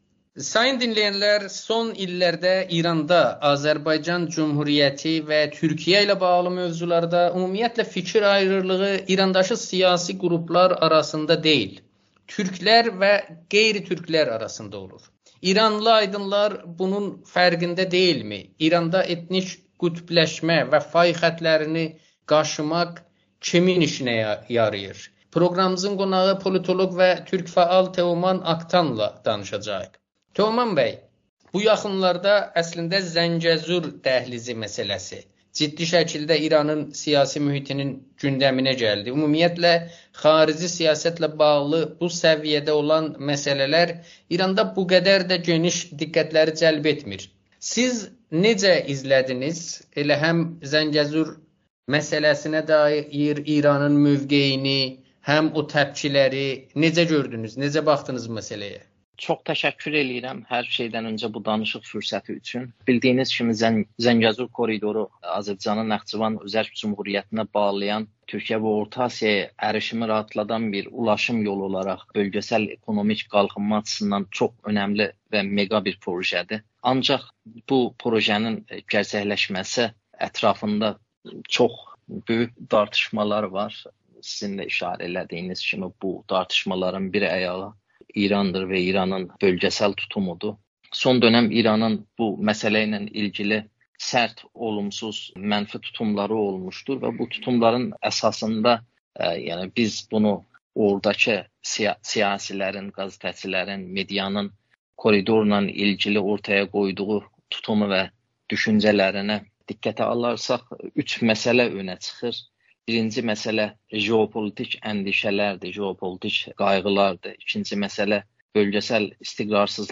Amerikanın Səsi ilə söhbətində